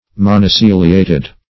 Search Result for " monociliated" : The Collaborative International Dictionary of English v.0.48: Monociliated \Mon`o*cil"i*a`ted\, a. [Mono- + ciliated.]